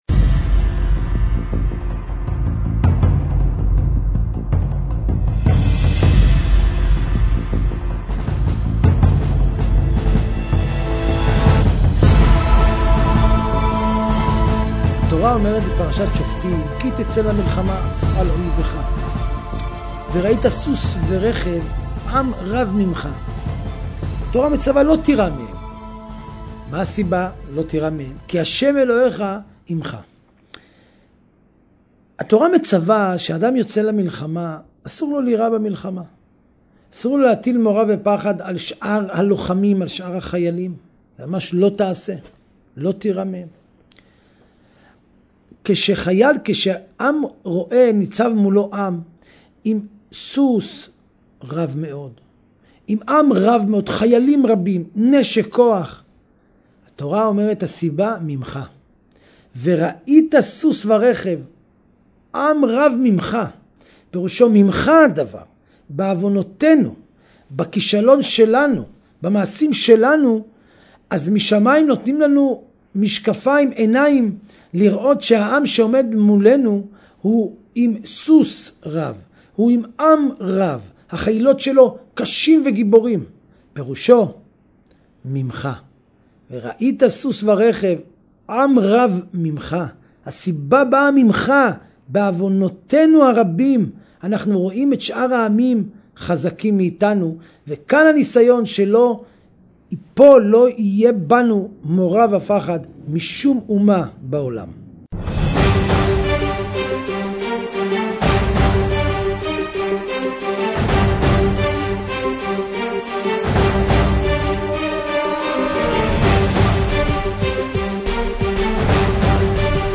torah lesson